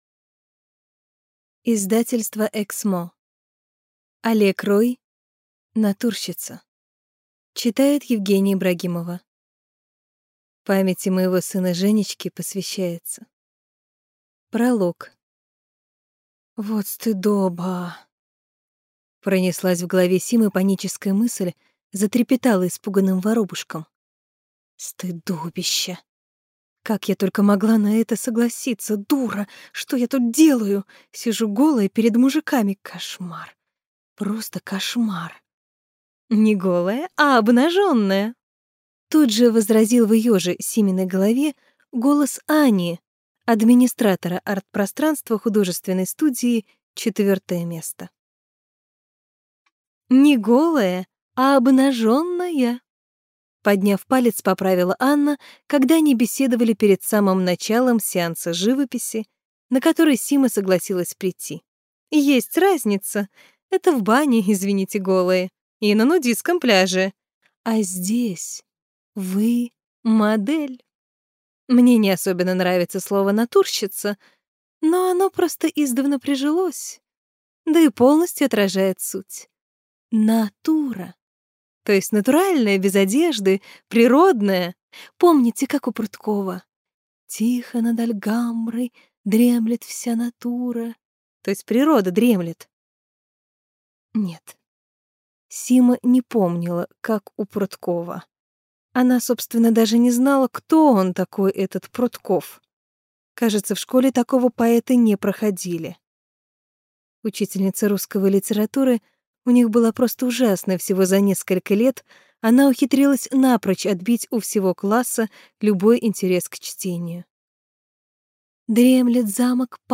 Аудиокнига Натурщица | Библиотека аудиокниг